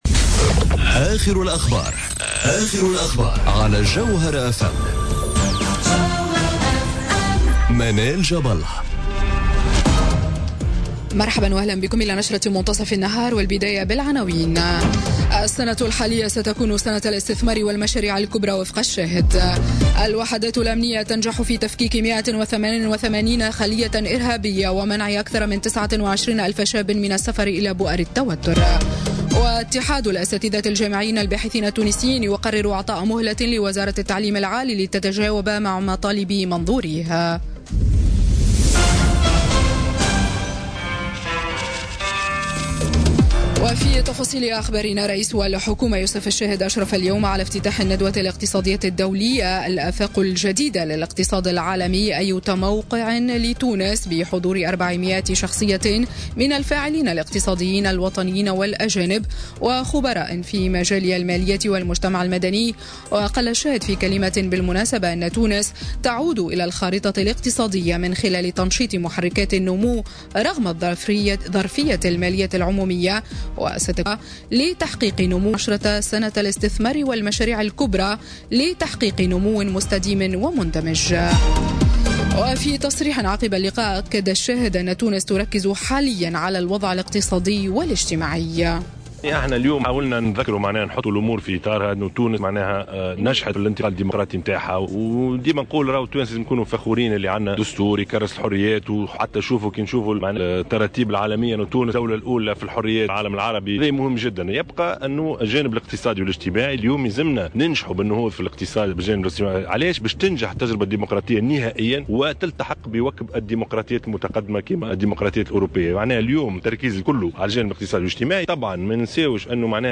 نشرة أخبار منتصف النهار ليوم الإثنين 29 جانفي2017